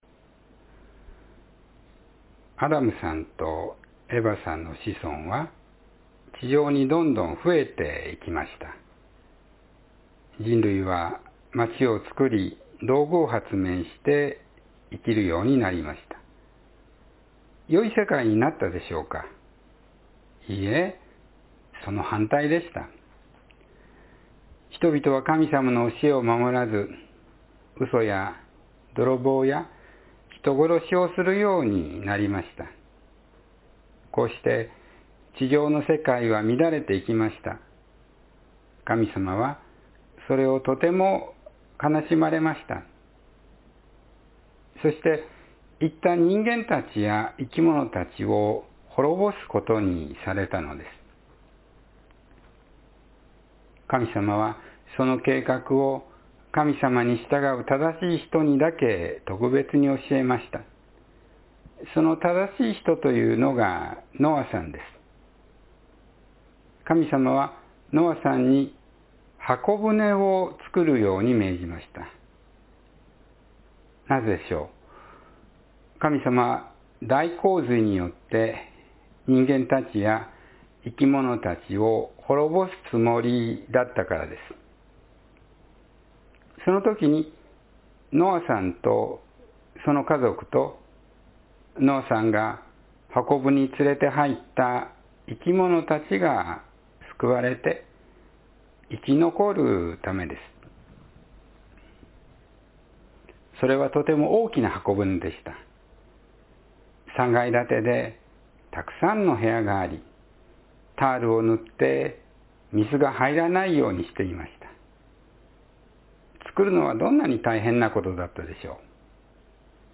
箱舟を造ったノア（2026年2月8日・子ども説教）